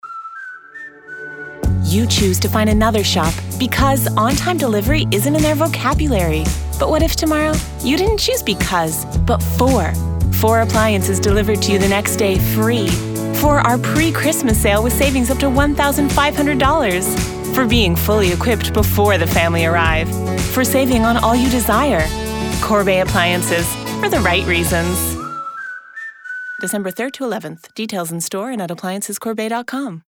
Publicité (Corbeil) - ANG